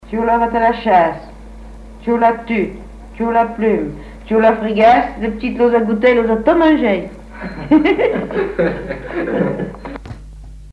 Enfantines - rondes et jeux
formulette enfantine : jeu des doigts